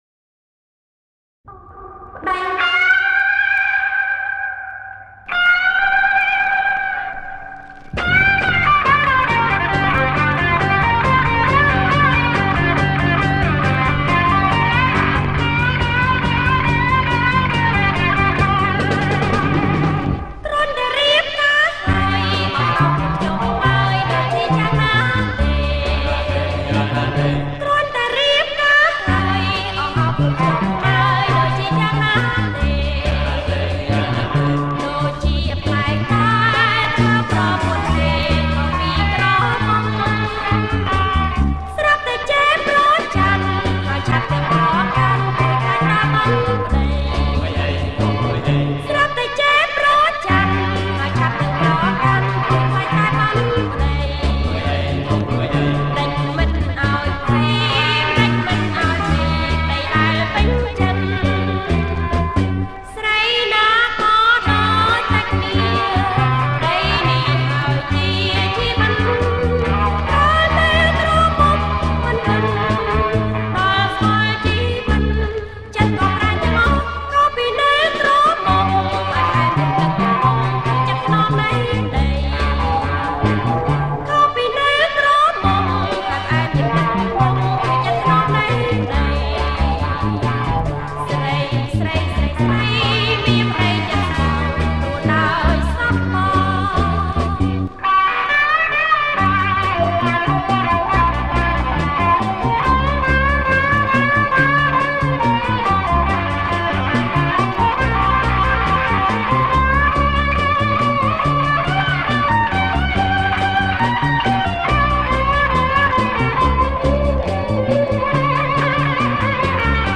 • ប្រគុំជាចង្វាក់ SLOW ROCK